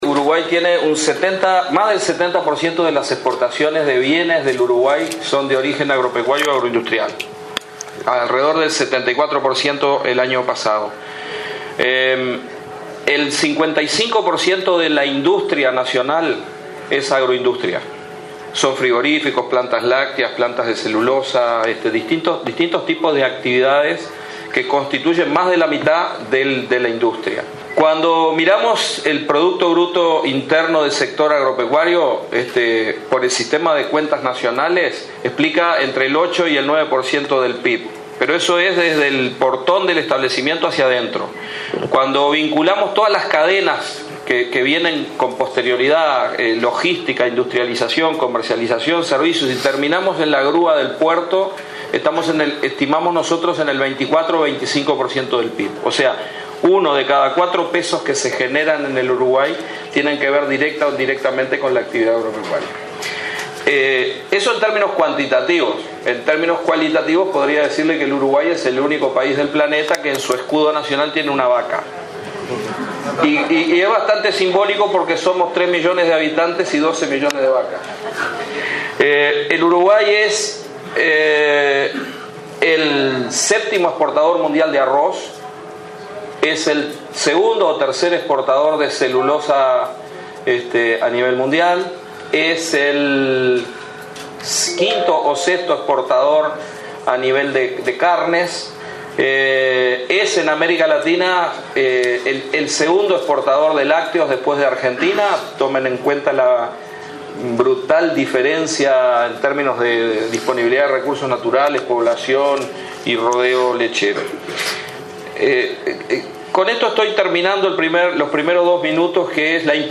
“Uno de cada cuatro pesos que se generan en el país tiene que ver con la actividad agropecuaria”, argumentó el ministro de Ganadería, Tabaré Aguerre, ante empresarios internacionales y nacionales en el foro sobre inversiones de la Unión Europea en Uruguay. El adecuado clima de negocios y las ventajas en la producción de bienes intensivos impactan en la inversión extranjera en el sector agropecuario y agroindustrial, dijo.